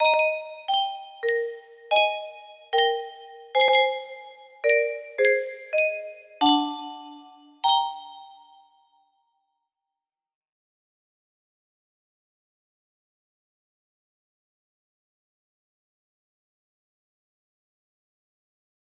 Lullaby/Music Box Example music box melody